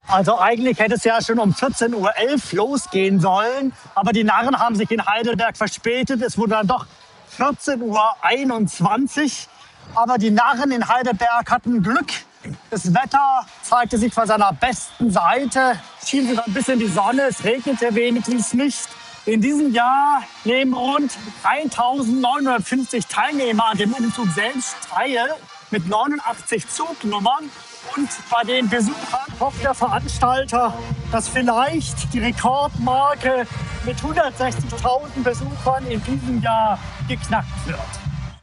Ausgelassene Stimmung beim Heidelberger Fastnachtsumzug